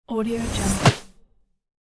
دانلود افکت صدای ترنزیشن سریع
افکت صدای ترنزیشن سریع یک گزینه عالی برای هر پروژه ای است که به انتقال و حرکت و جنبه های دیگر مانند انتقال، حرکت و انتقال نیاز دارد.
Sample rate 16-Bit Stereo, 44.1 kHz
Looped No